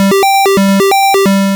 retro_synth_beeps_01.wav